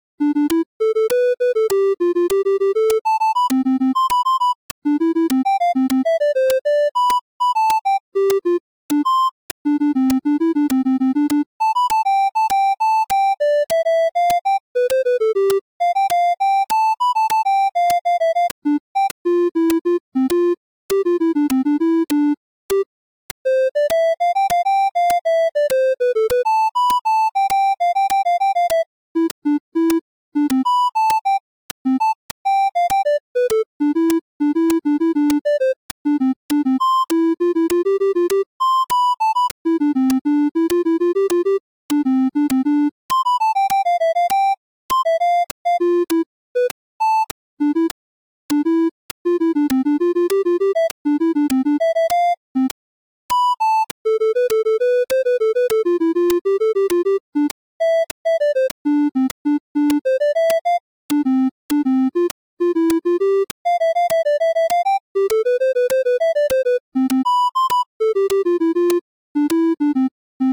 By diatonic I mean taking only the notes from a ionian major scale in this case.
Here you can find a C++ code to generate silly, sometimes funny, melodies.
As you can see in the code, I limit the span to two octaves, so you will see how sometimes the algorithm insists bouncing on these two walls.
I have also added a noise instrument to serve as a metronome, marking at 2nd and 4th beats of each measure, to help following the score.